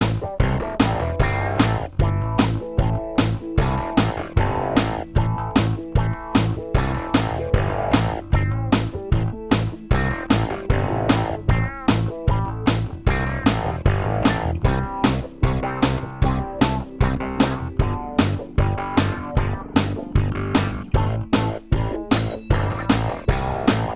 Minus All Guitars Pop (1970s) 3:03 Buy £1.50